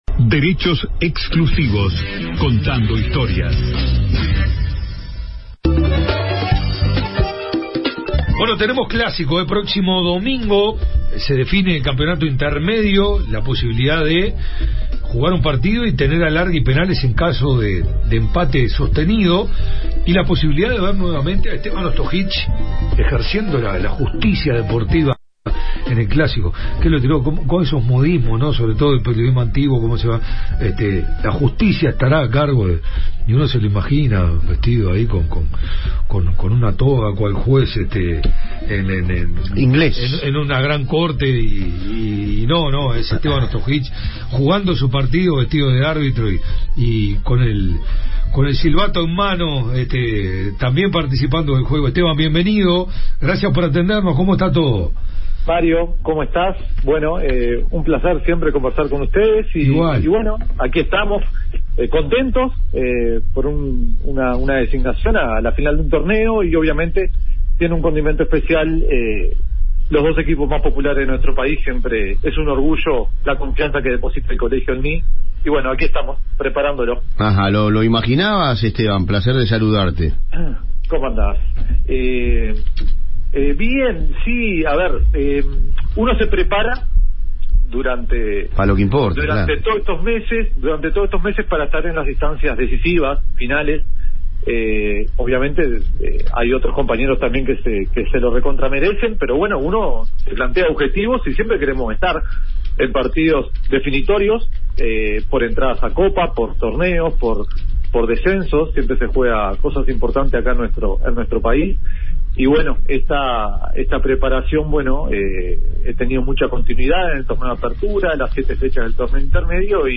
El árbitro del clásico del próximo domingo de la final del torneo Intermedio entre Nacional y Peñarol habló en Derechos Exclusivos del análisis que hace la cuaterna antes del partido, de porqué es inédita esta designación, quién es el mejor juez del mundo y porqué el mundial de clubes va a servir para que el público entienda a los jueces.